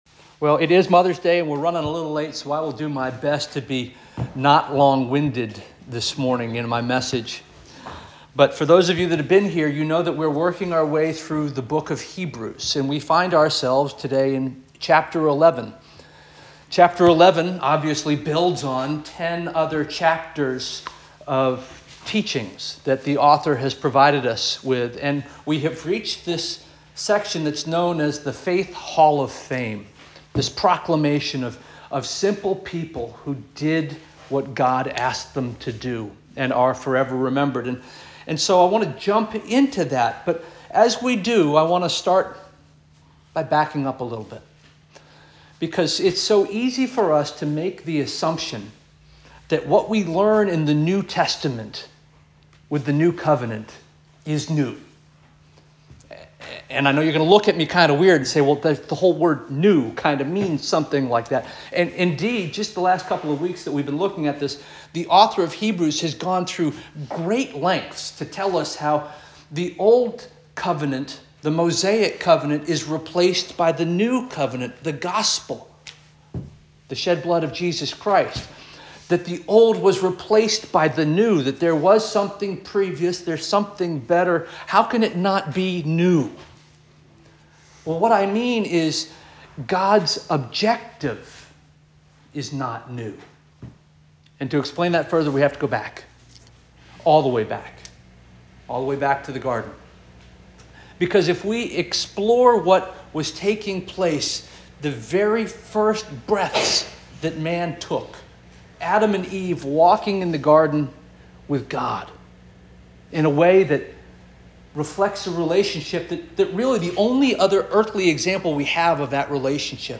May 12 2024 Sermon